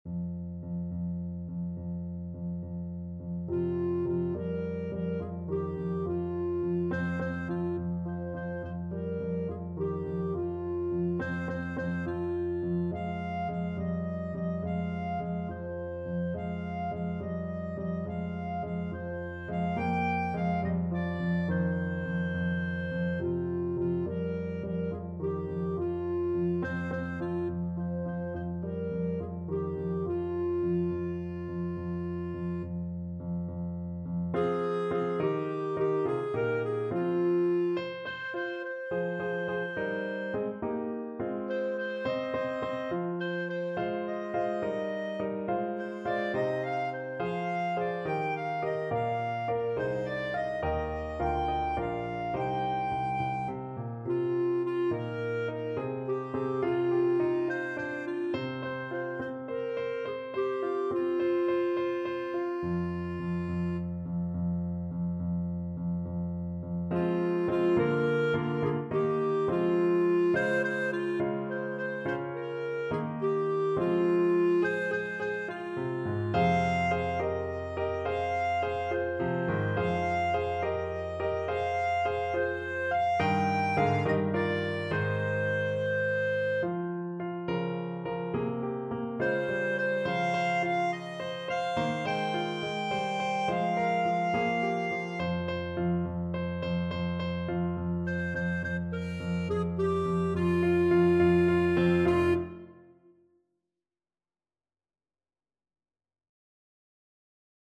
Clarinet
3/4 (View more 3/4 Music)
F5-G6
C minor (Sounding Pitch) D minor (Clarinet in Bb) (View more C minor Music for Clarinet )
Quick one in a bar (. = c. 70)
Traditional (View more Traditional Clarinet Music)